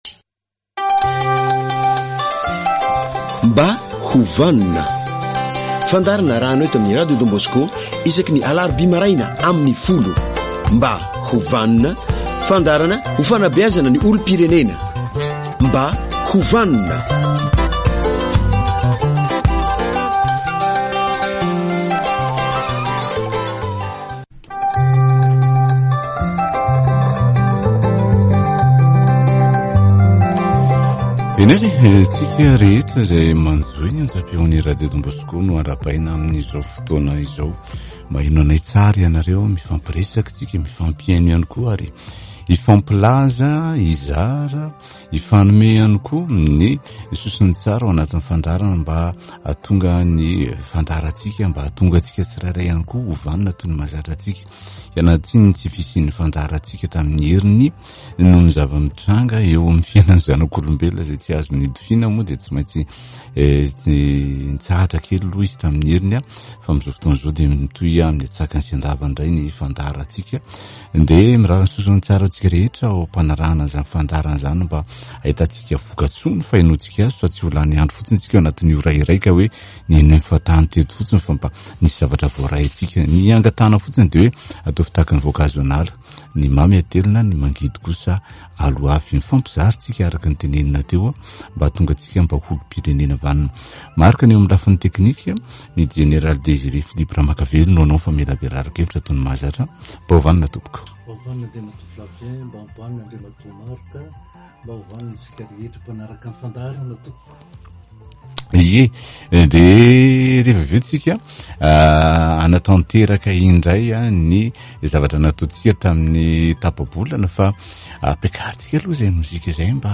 Fandaharana "mba ho vanona", iarahana amin'i Jeneraly Désiré Philippe Ramakavelo.